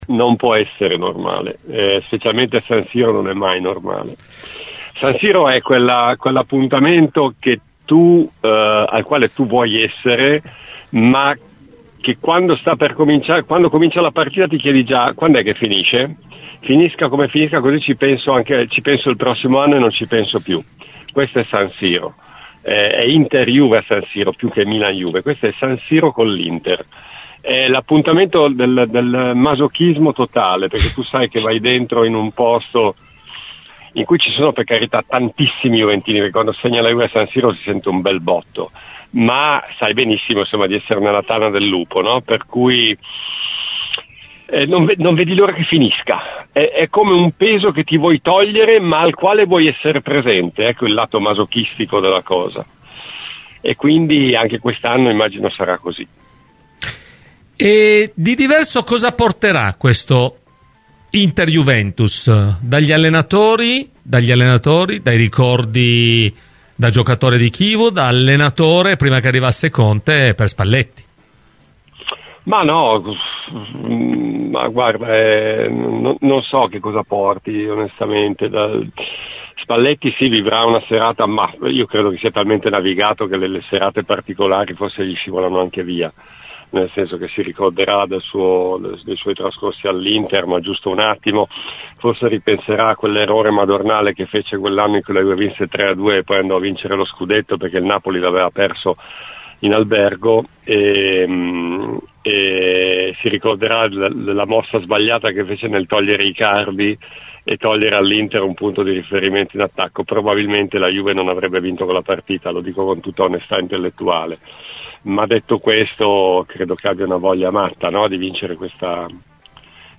Nel corso di “Cose di Calcio” su Radio Bianconera